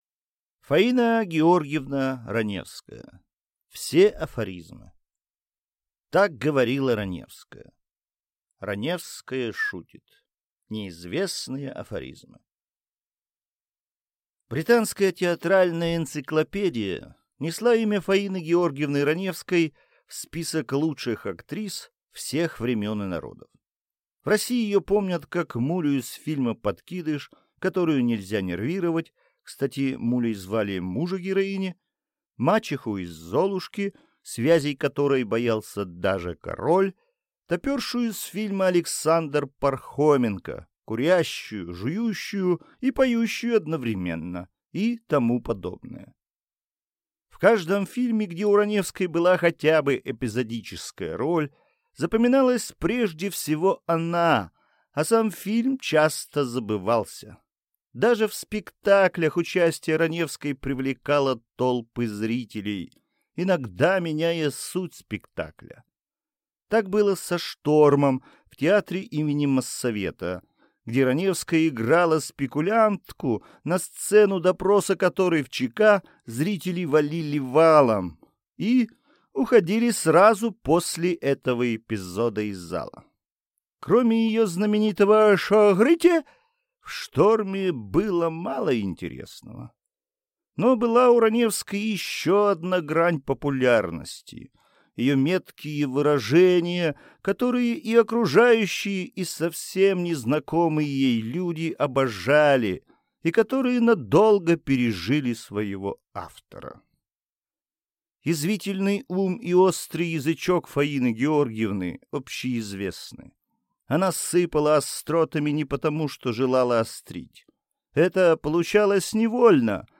Aудиокнига Все афоризмы